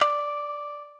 shamisen_d.ogg